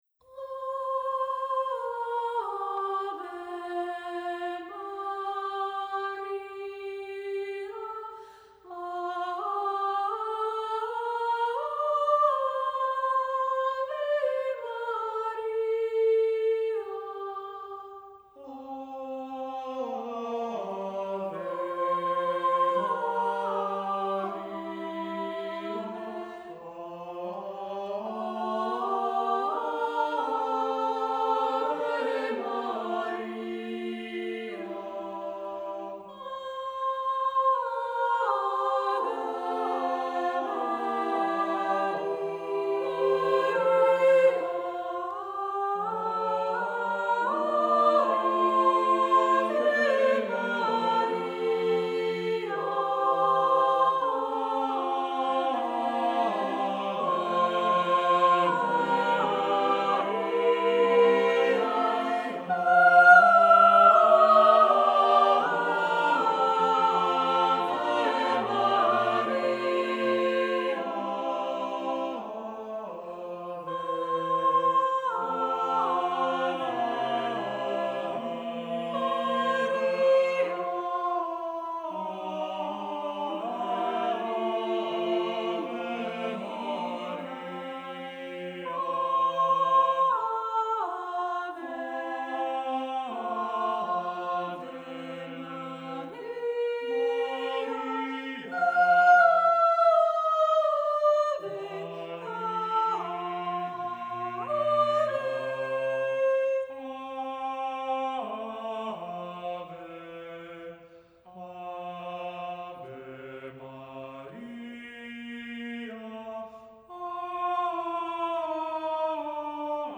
Accompaniment:      A Cappella
Music Category:      Early Music